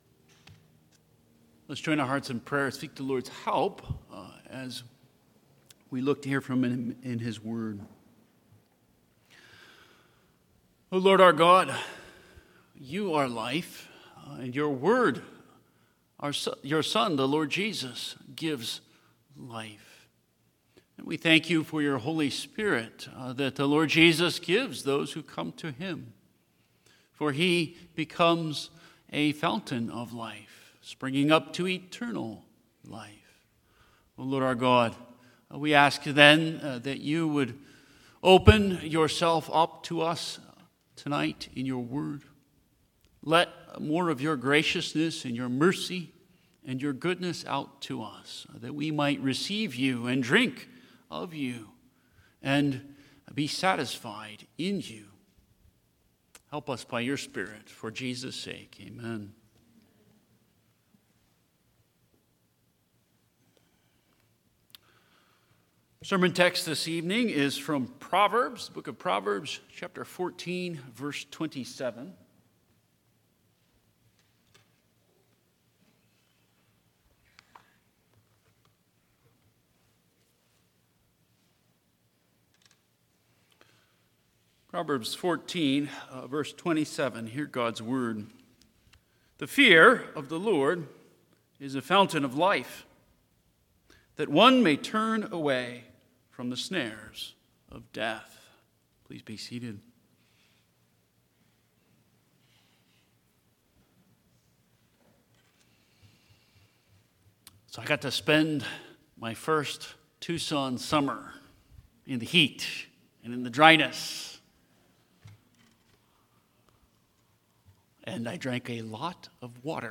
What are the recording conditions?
September 14, 2025 PM service